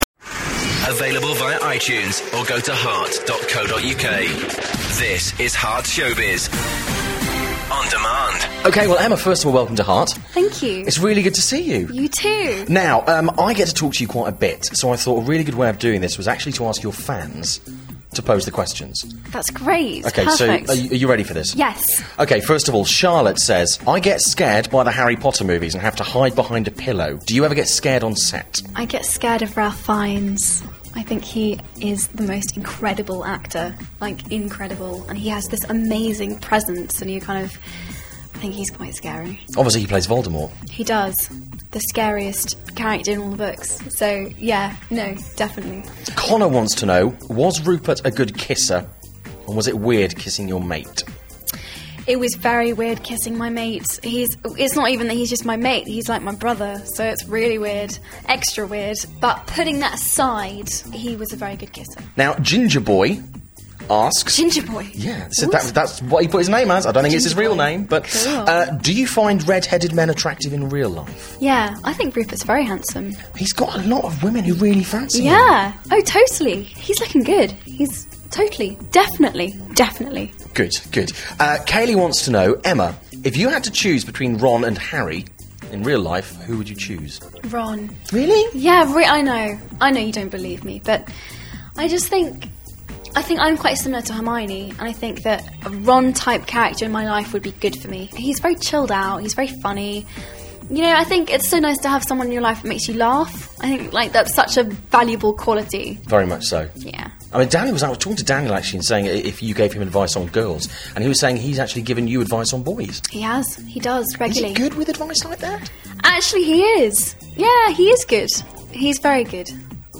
It’s a good interview, I think you’ll like it.
It made me really happy hearing them especially the one from heart, you just got to love her accent.